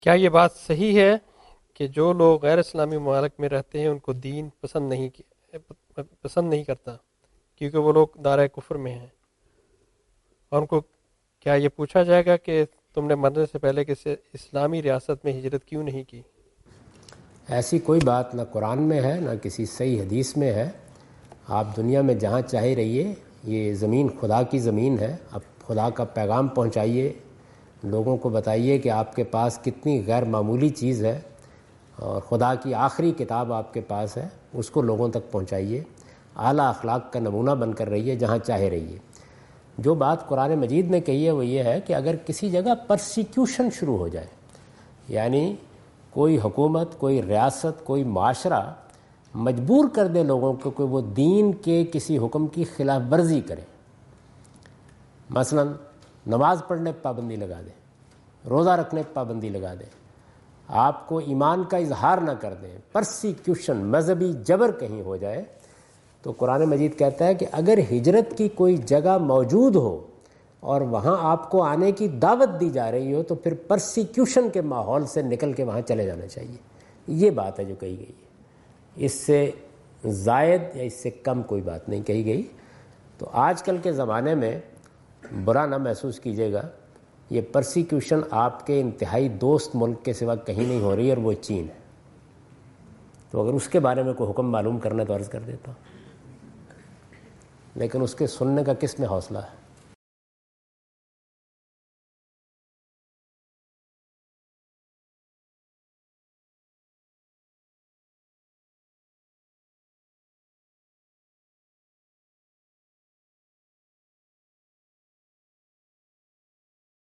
Javed Ahmad Ghamidi answer the question about "migrating to Muslim countries" in Macquarie Theatre, Macquarie University, Sydney Australia on 04th October 2015.
جاوید احمد غامدی اپنے دورہ آسٹریلیا کے دوران سڈنی میں میکوری یونیورسٹی میں "غیر مسلم ممالک سے مسلم ممالک کی طرف ہجرت" سے متعلق ایک سوال کا جواب دے رہے ہیں۔